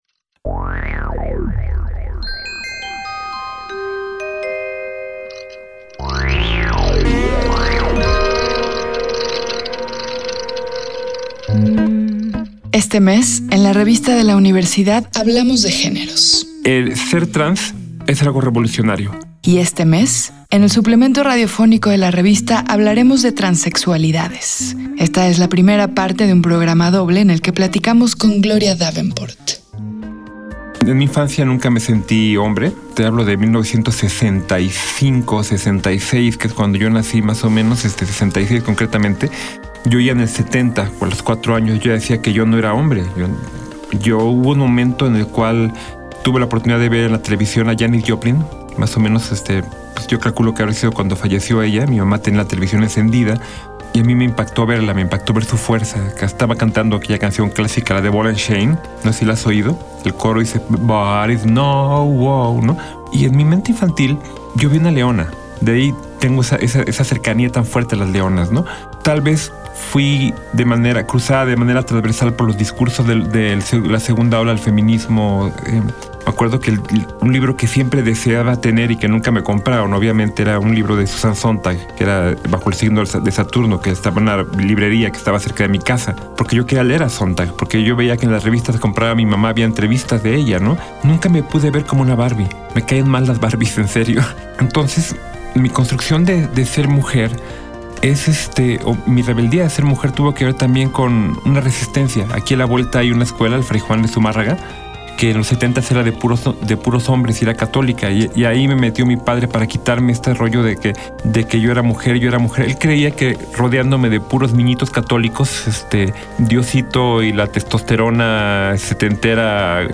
Fue transmitido el jueves 7 de marzo de 2019 por el 96.1 FM.